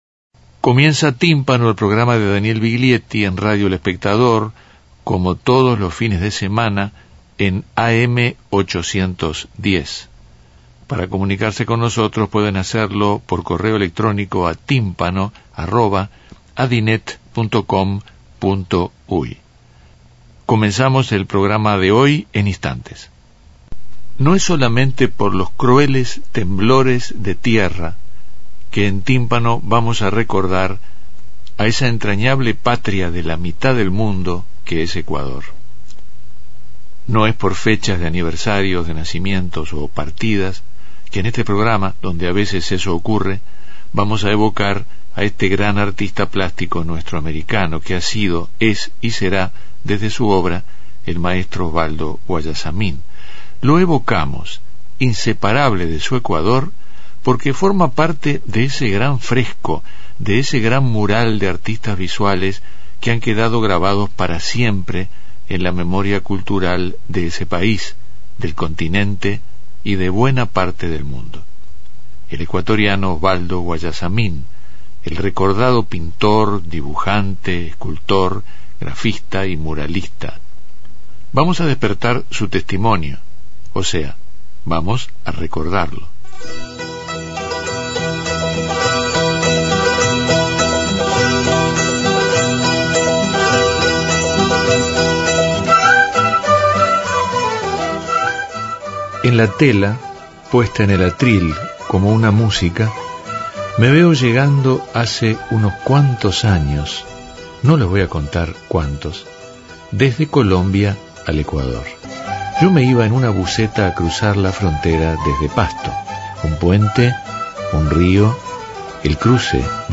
En histórica entrevista Daniel Viglietti nos acerca el recuerdo del maestro Oswaldo Guayasamín.